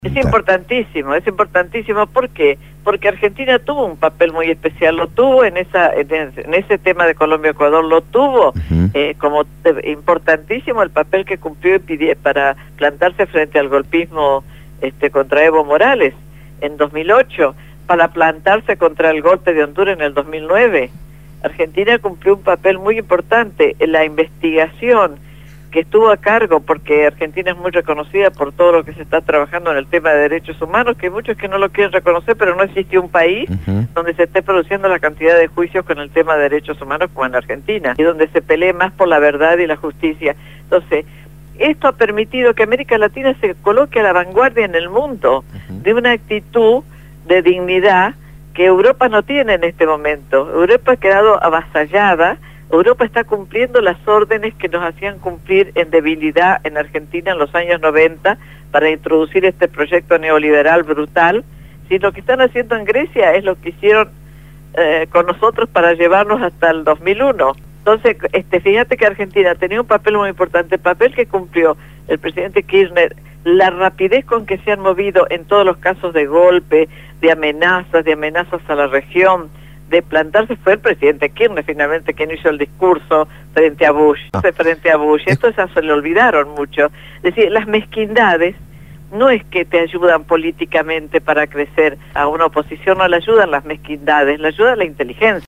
Entrevistada